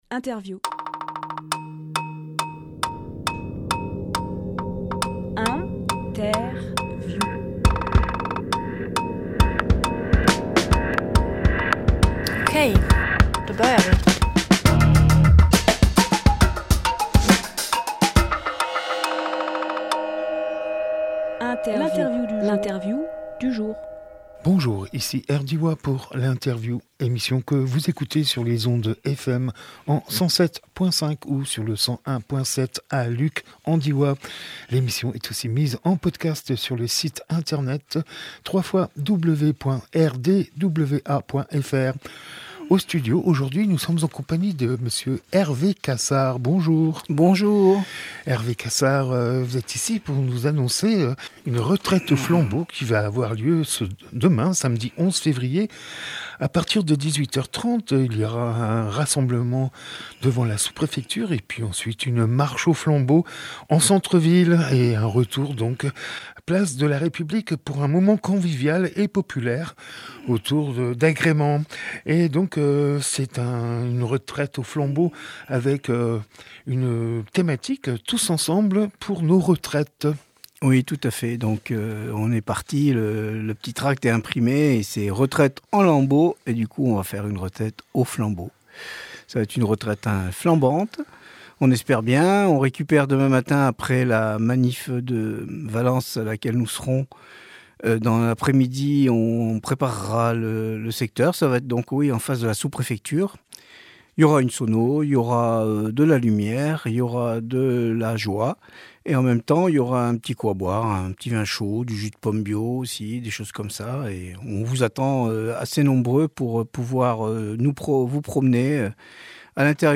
Emission - Interview Retraites aux flambeaux et autres lambeaux Publié le 10 février 2023 Partager sur…
10.02.23 Lieu : Studio RDWA Durée